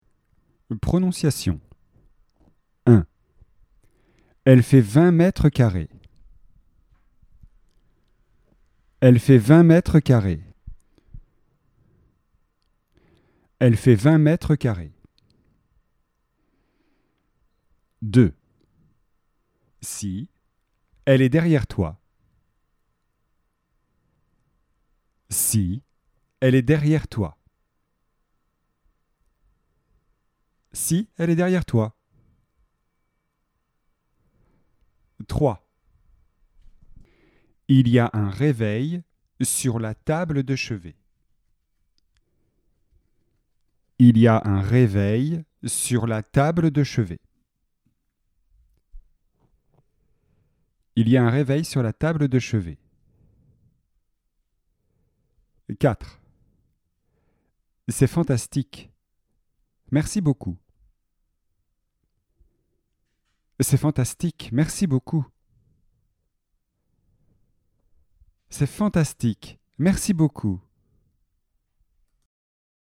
🔷 Prononciation
chambre-prononciation.mp3